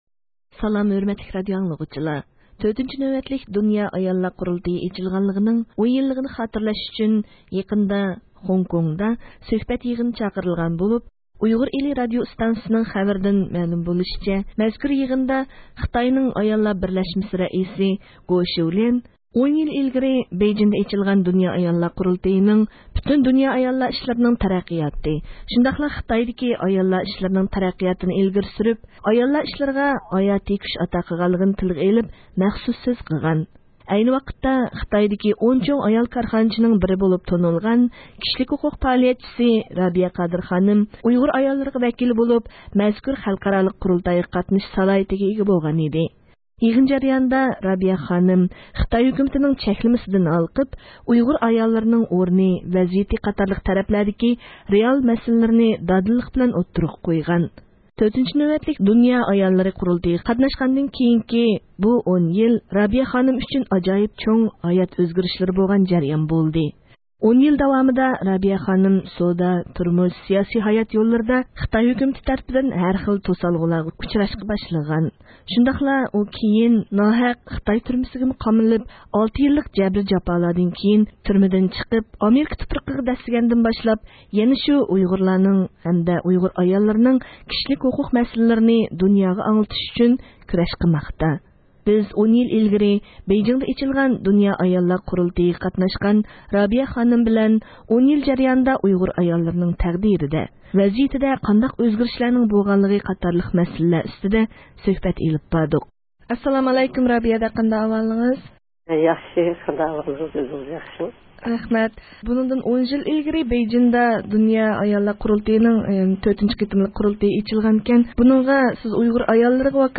سۆھبەت